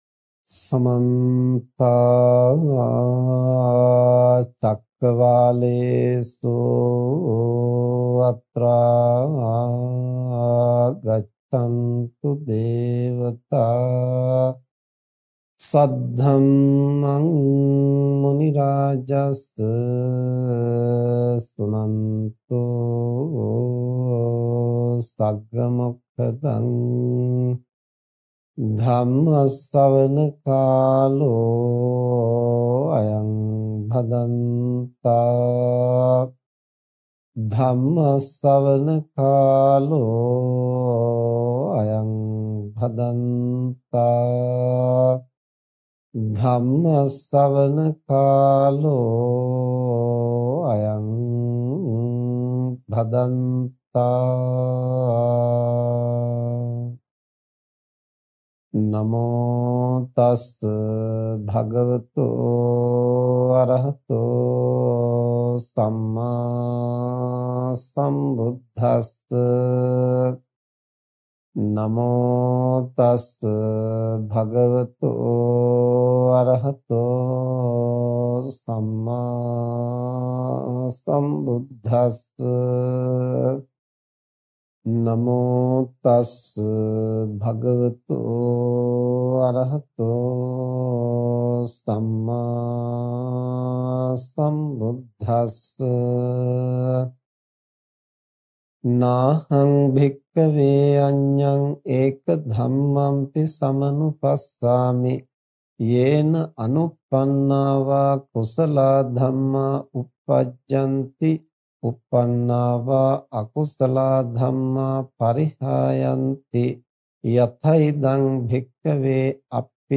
යම් භාජනයකට ජලය පිරවීමේදී එය පිරෙන ලෙස අපගේ ආශාවන් අනුව වැඩි වැඩියෙන් දේවල් අත්පත් කරගැනීමෙන් අපගේ මනස පිරවිය නොහැකි බව ඉතා පැහැදිලි ලෙස මෙම අල්පේච්ඡතාවය හා බැඳුනු මැදුම් පිළිවෙත පිලිබඳ ධර්ම දේශනාවෙන් පහදා දේ.